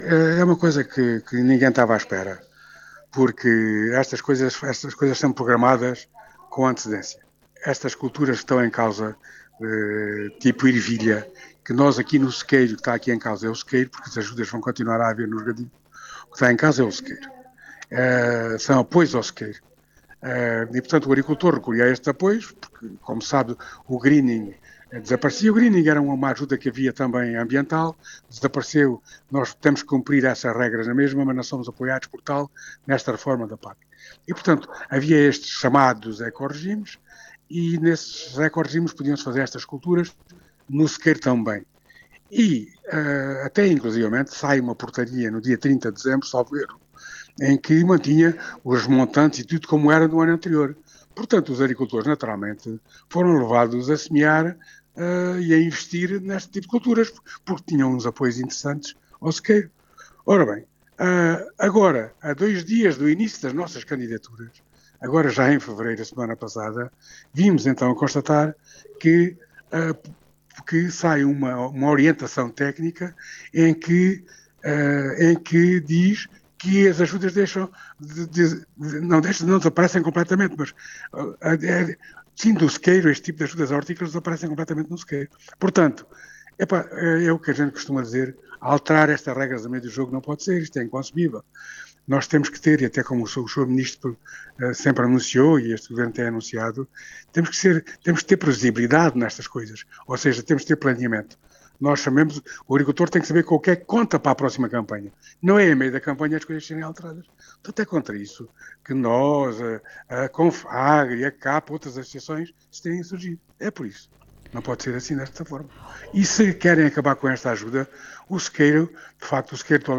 Agricultores exigem reposição dos apoios à horticultura (entrevista)